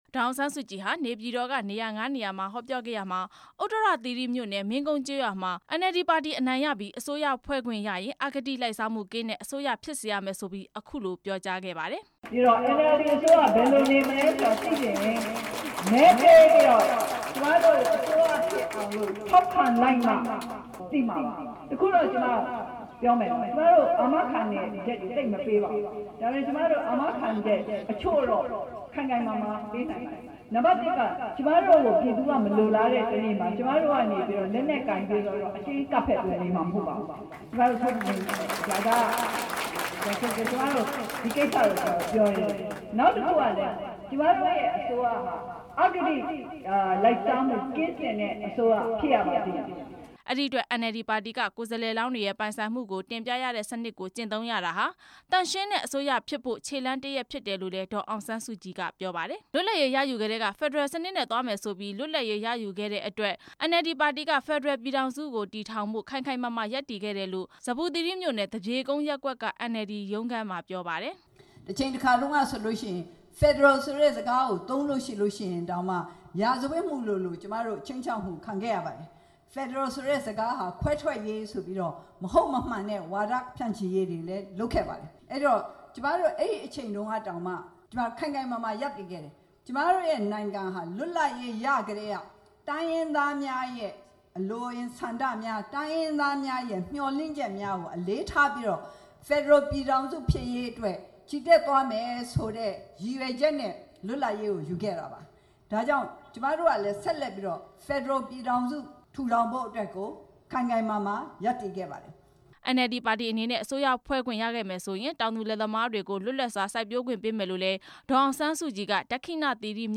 ဒေါ်အောင်ဆန်းစုကြည် ရဲ့ နေပြည်တော် ရွေးကောက်ပွဲ ဆိုင်ရာ ဟောပြောပွဲ
အမျိုးသားဒီမိုကရေစီအဖွဲ့ချုပ် ဥက္ကဌ ဒေါ်အောင်ဆန်းစုကြည်ဟာ ဒီကနေ့ နေပြည်တော်ကောင်စီ နယ်မြေက ဥတ္တရသီရိ၊ ဇမ္ဗူသီရိနဲ့ ဒက္ခိဏသီရိမြို့နယ်တွေက နေရာငါးနေရာမှာ မဲပေးကြဖို့ကို အသိပညာပေးဟောပြောပွဲတွေ ပြုလုပ်ခဲ့ပါတယ်။